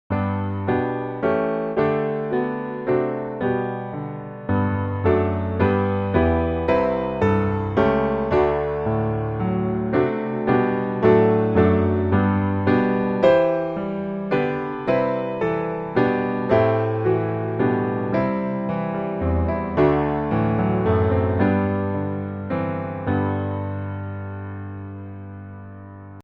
Piano Hymns
G Majeur